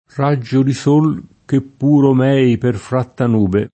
r#JJo di S1l, ke pp2ro m$i per fr#tta n2be] (Dante)